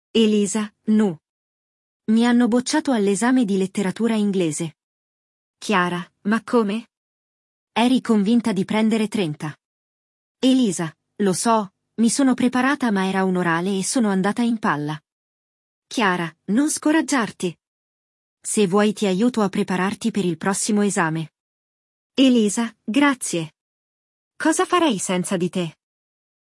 Neste episódio, duas amigas conversam sobre uma prova de literatura em que uma delas se deu mal.
Il dialogo